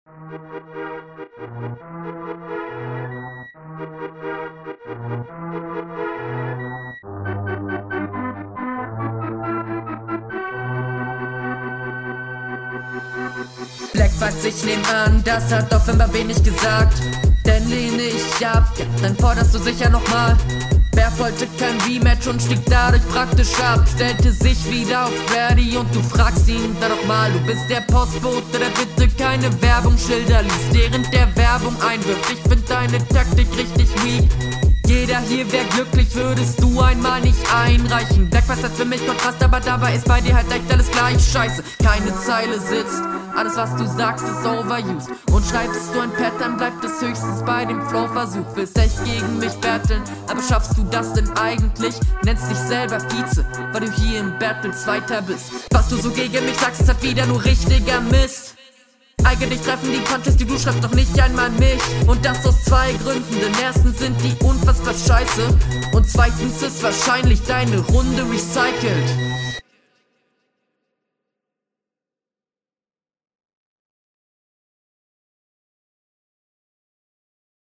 Flow: Sind n paar coole Patterns dabei und die sind cool Rüber gebracht Text: Das …